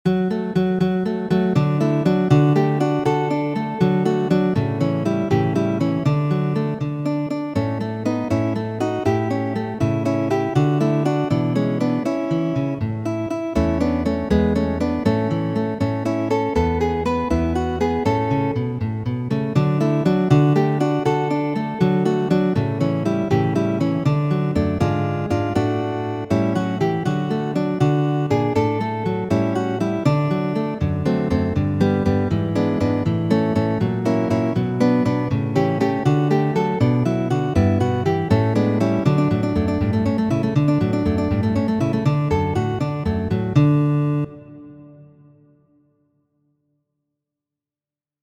Nesto muzikaĵo por du gitaroj, de Mateo Karkasi.